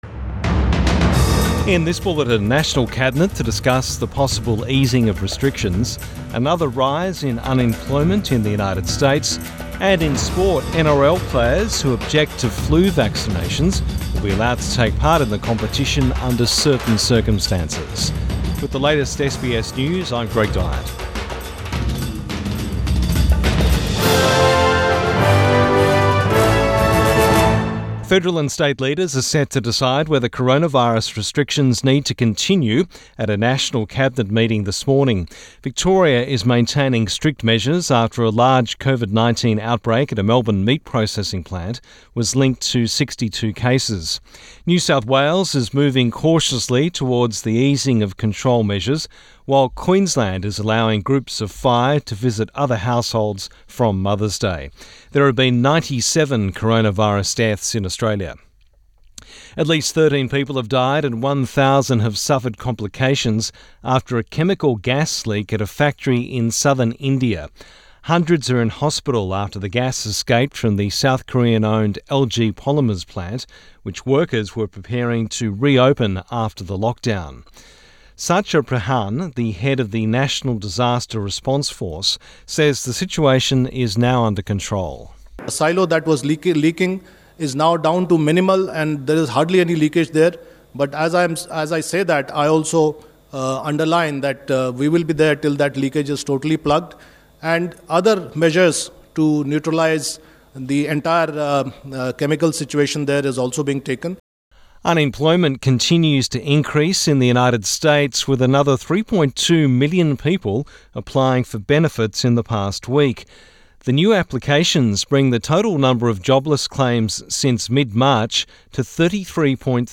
AM bulletin 8 May 2020